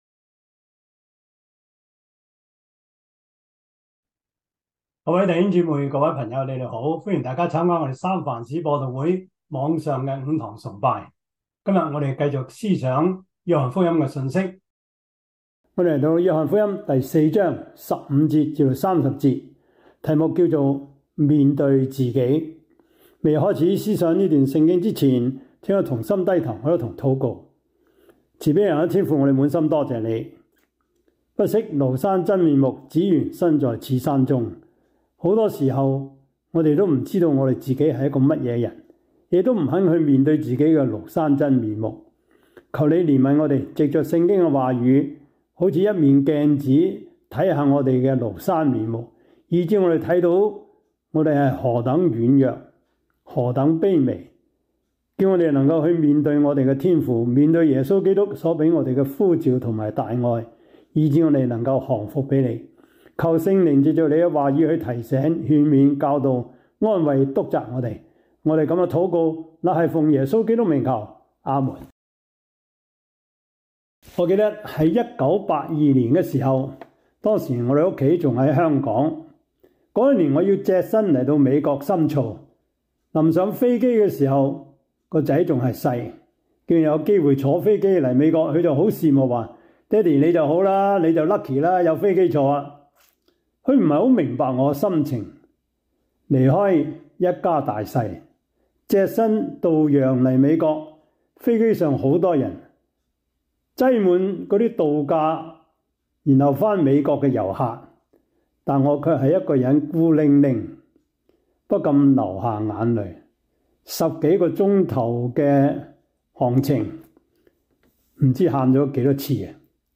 約翰福音 4:15-30 Service Type: 主日崇拜 約翰福音 4:15-30 Chinese Union Version
」 Topics: 主日證道 « 開墾心靈的土壤 基礎神學 (二) – 第八課 »